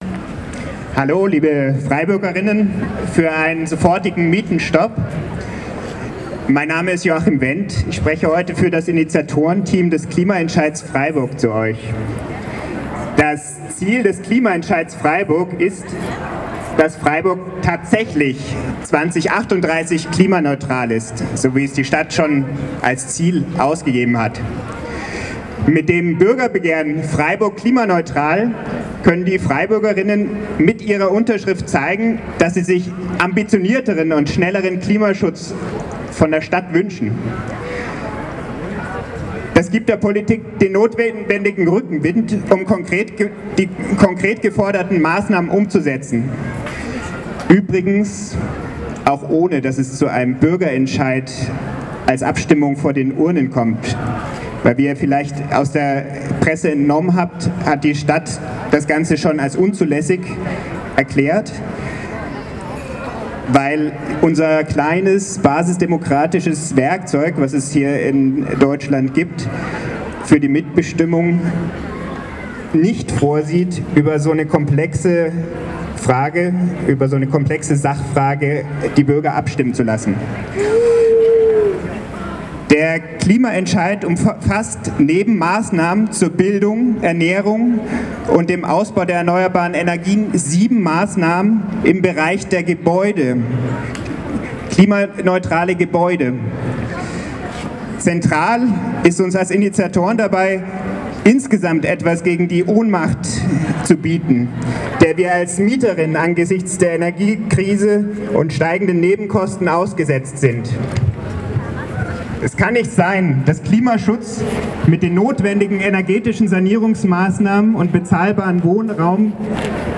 Mietenstopp Aktionstag in Freiburg: Reden u.a. vom Klimacamp, Klimaentscheid, MieterInnen und DGB